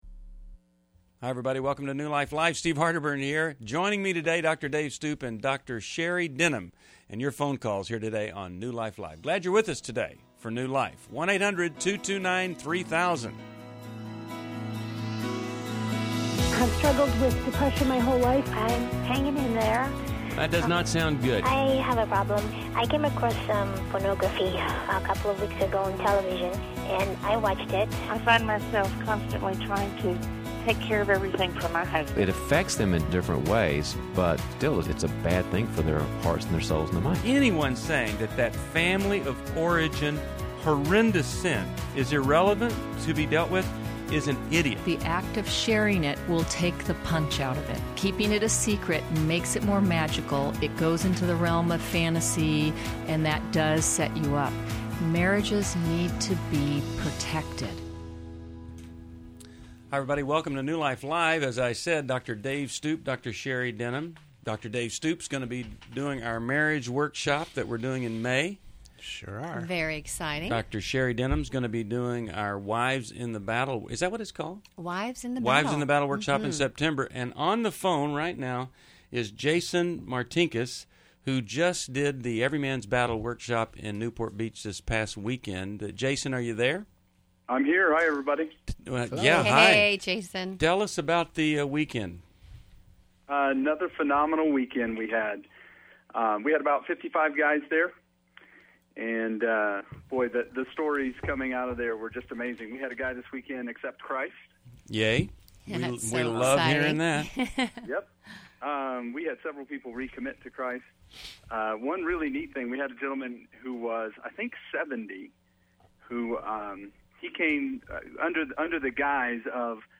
Explore sexual addiction, affairs, and dating dilemmas with expert insights on New Life Live: April 12, 2011. Callers share personal struggles and seek guidance.